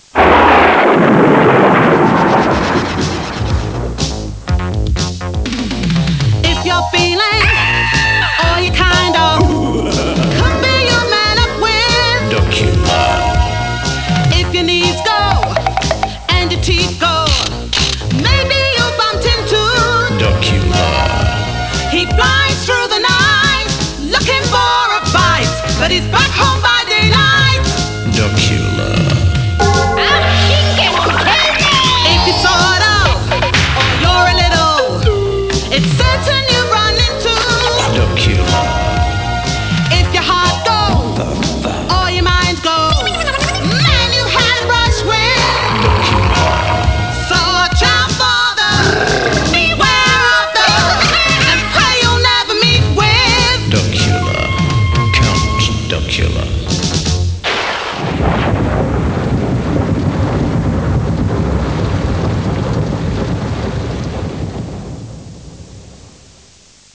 end theme music